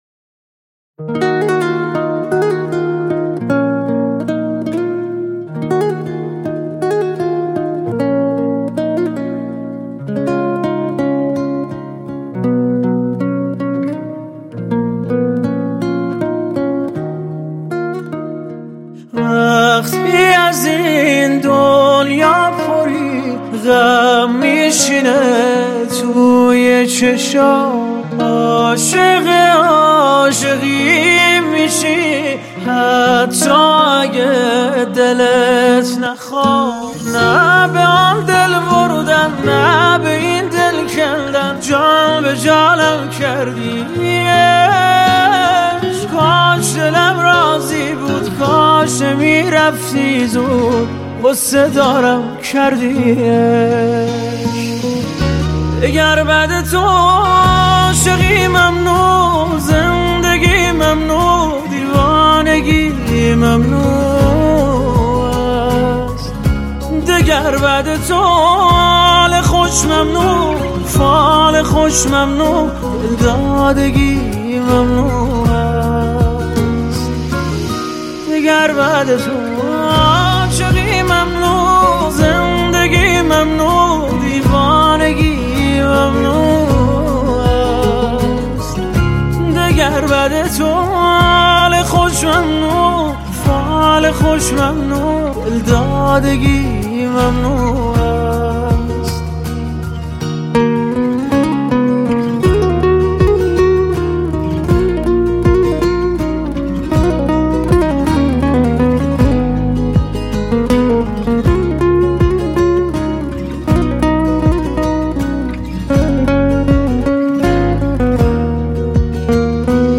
غمگین و داغون کننده